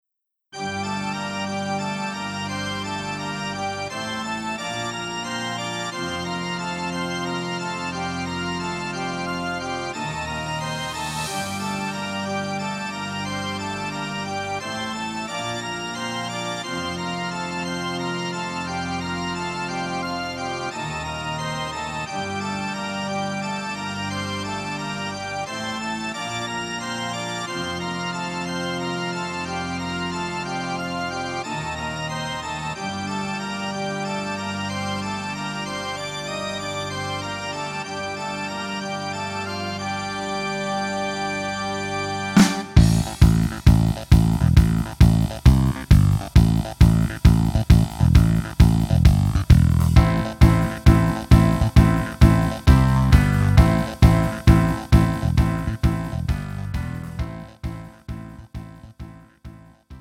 음정 -1키 2:24
장르 가요 구분 Pro MR
Pro MR은 공연, 축가, 전문 커버 등에 적합한 고음질 반주입니다.